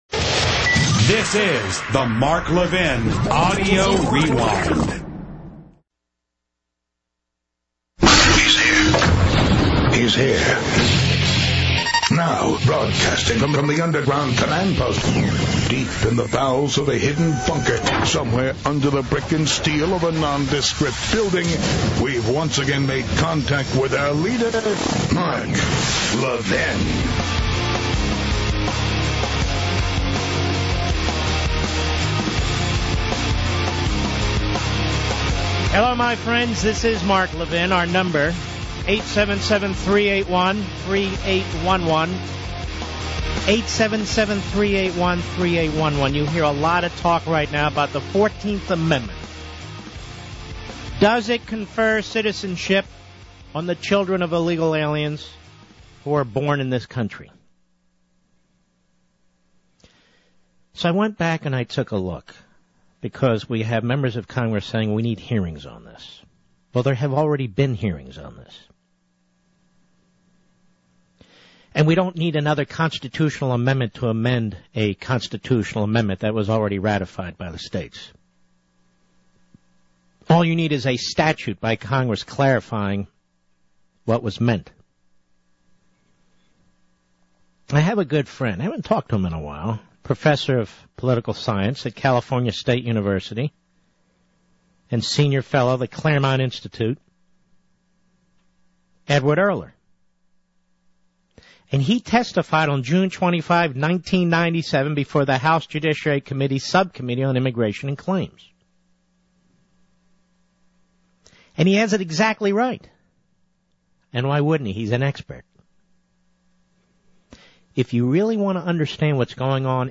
Below is the audio of Mark’s dialogue from Friday, Aug 13th, 2010.